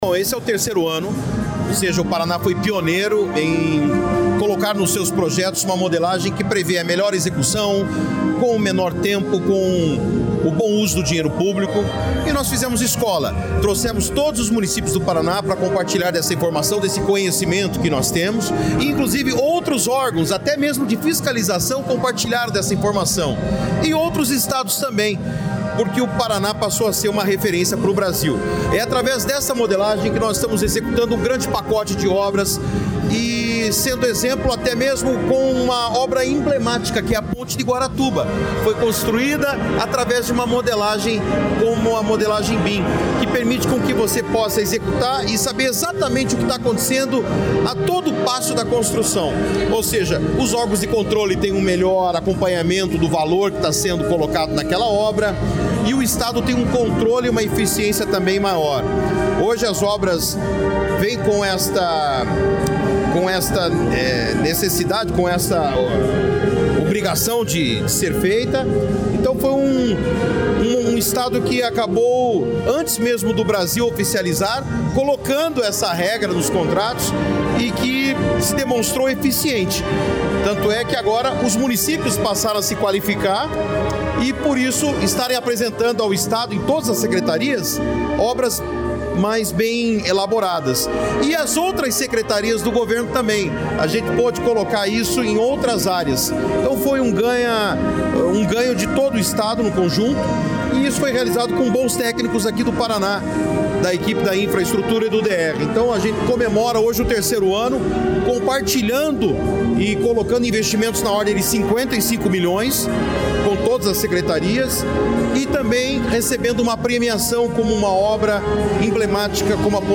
Sonora do secretário Estadual da Infraestrutura e Logística, Sandro Alex, sobre a Jornada BIM Paraná